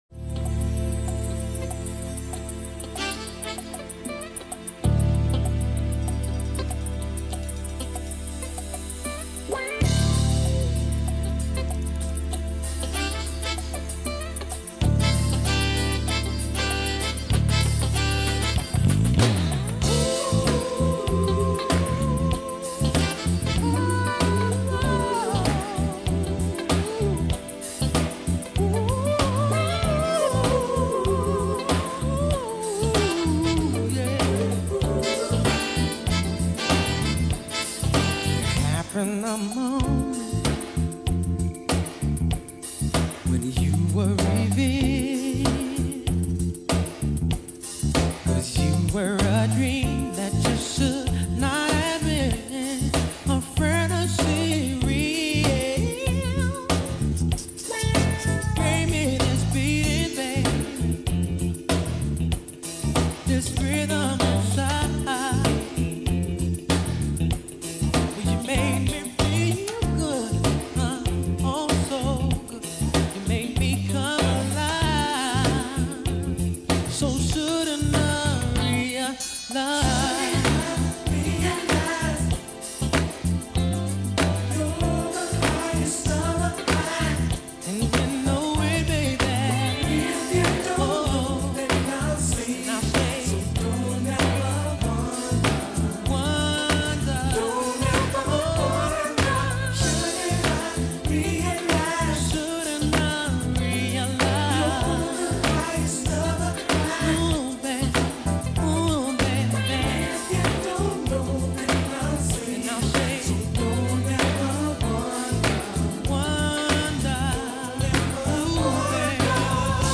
R&B
mini concert